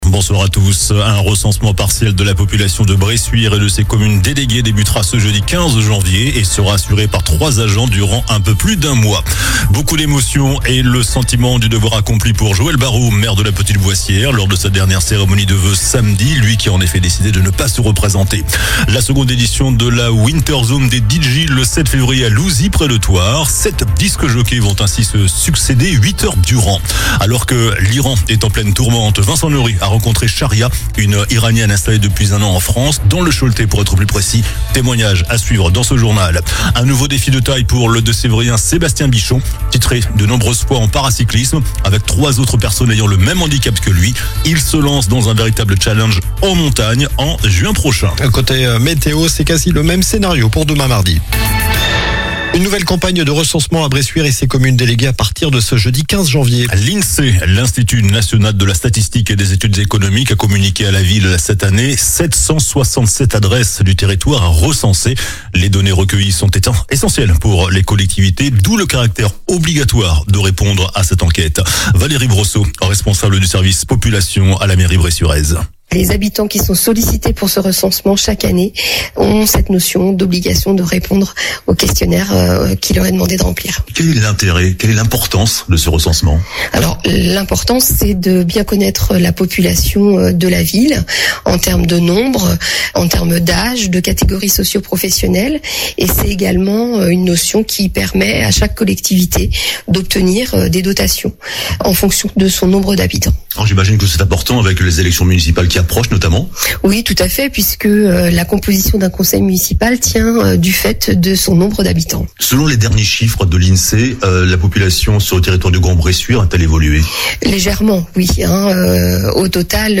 JOURNAL DU LUNDI 12 JANVIER ( SOIR )